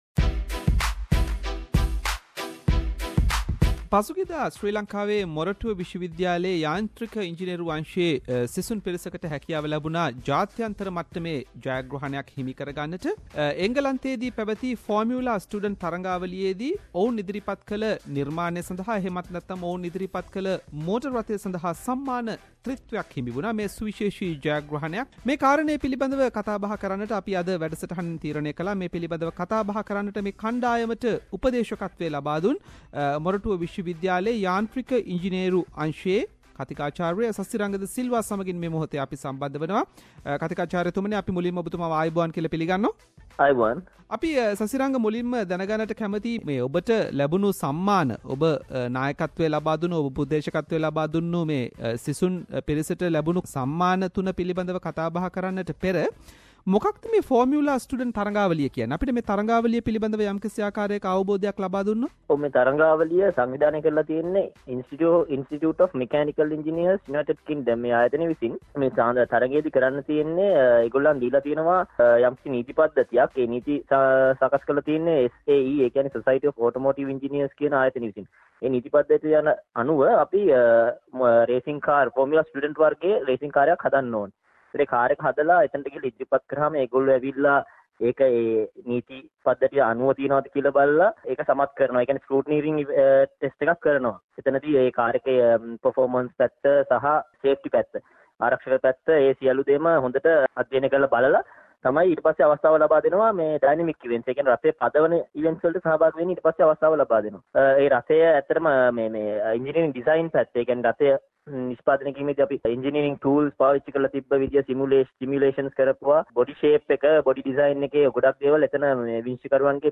SBS Sinhalese interviewed